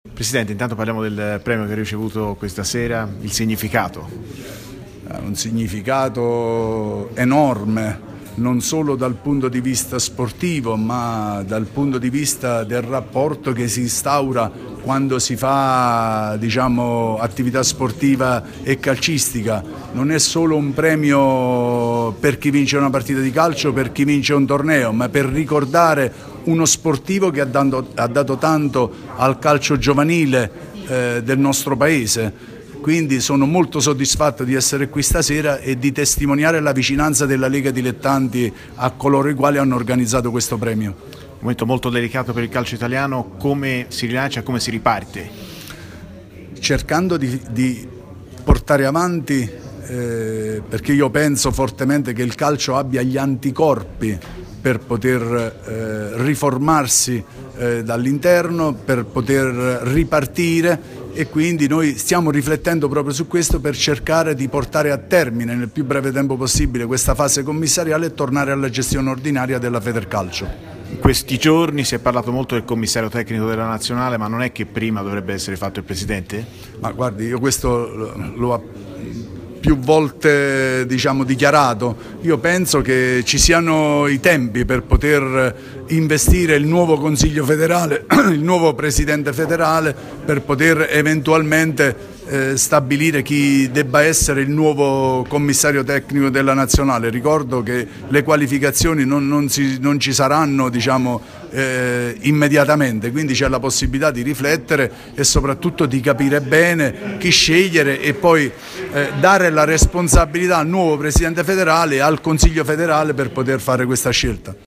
Il presidente della Lega Nazionale Dilettanti, Cosimo Sibilia, ha fatto il punto sul calcio italiano al termine del 'Premio Maestrelli' a Fiuggi.
Cosimo Sibilia, Presidente LND, intervistato